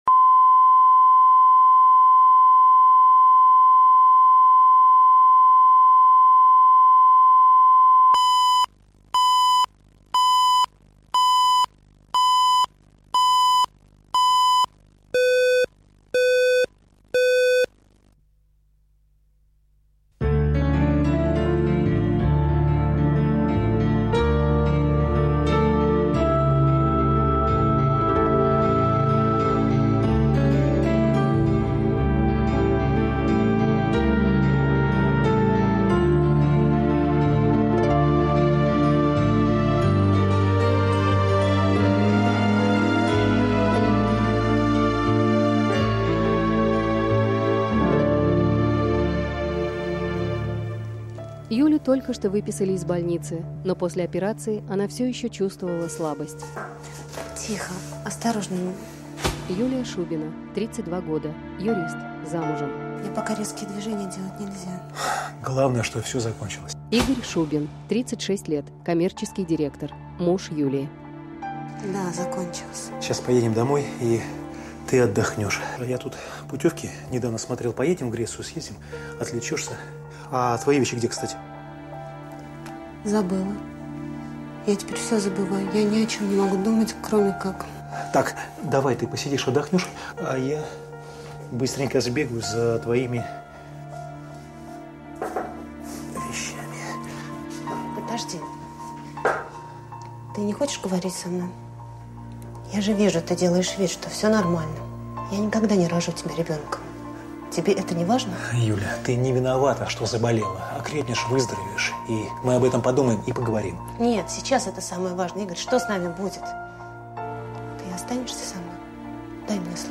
Аудиокнига Суррогатная мать | Библиотека аудиокниг